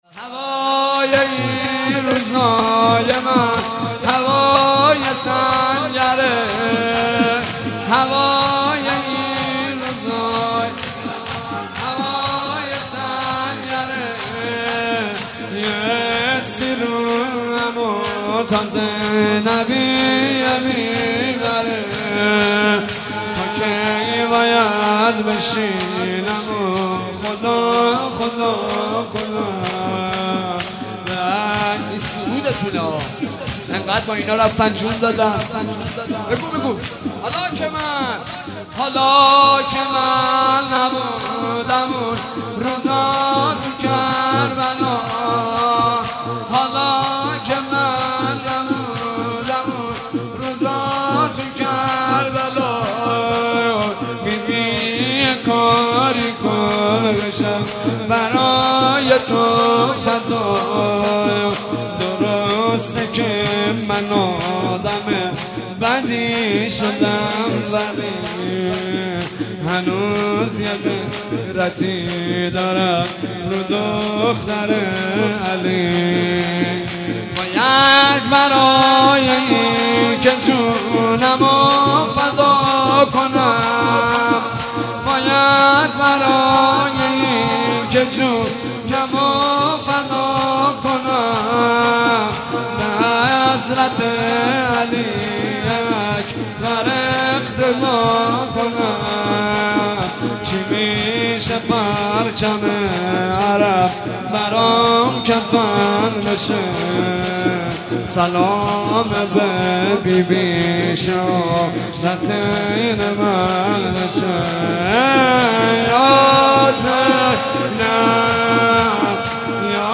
جلسه هفتگی هیت یا زهرا(س)